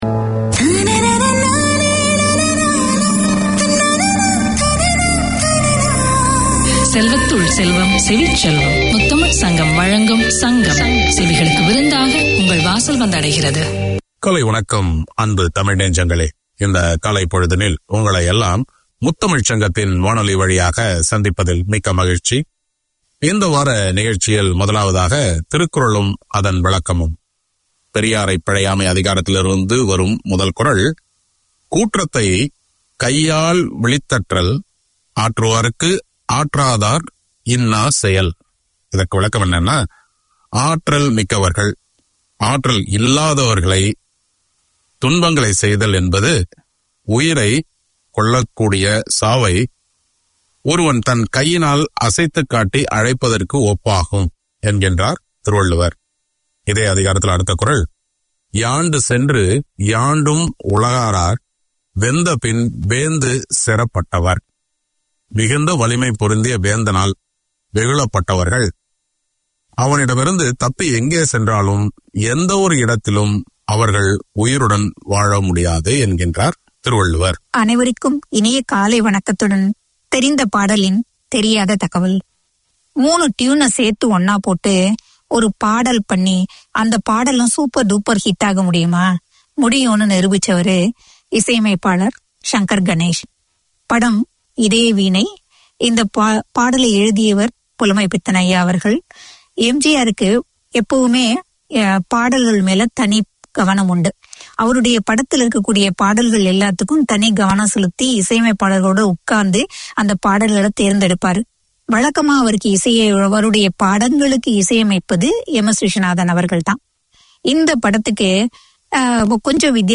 Tamil literature, poems, news and interviews - Melisai brings you current affairs, local and international events relevant to Tamils, with wit and humour. Each Sunday morning there’s the chance to hear local Tamil perspectives presented by Tamil speakers with a passion for the language and culture. The music is varied, the topics entertaining.